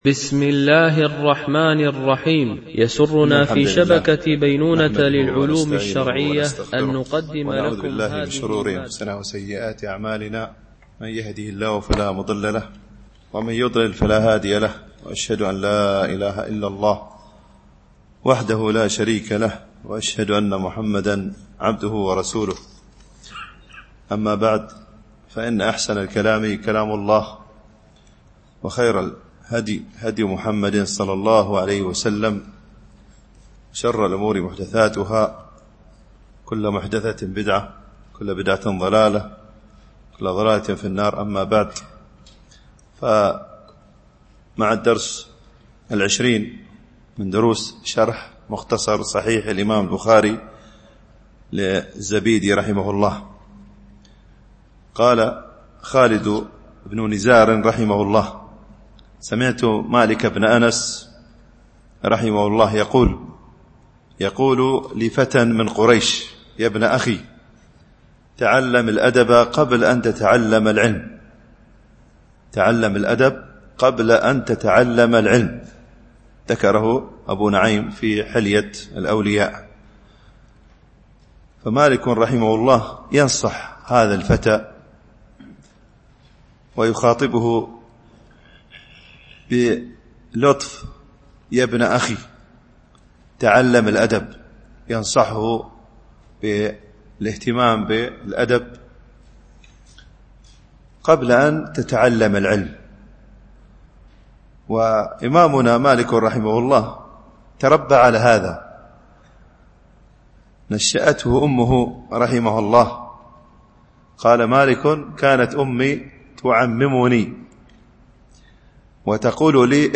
MP3 Mono 22kHz 32Kbps (CBR)